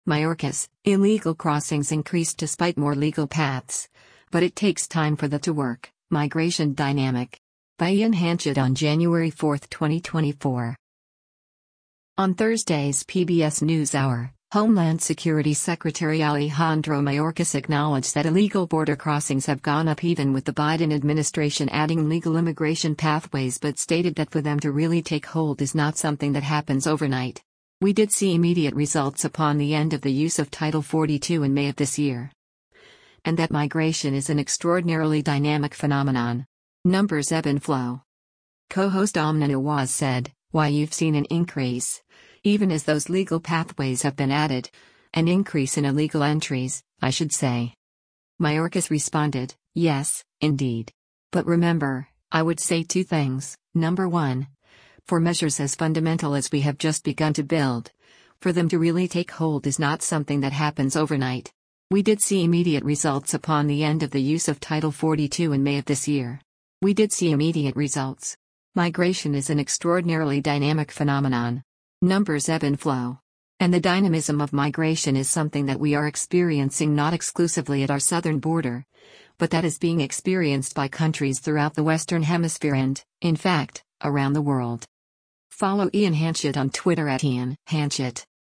Co-host Amna Nawaz said, “[Y]ou’ve seen an increase, even as those legal pathways have been added — an increase in illegal entries, I should say.”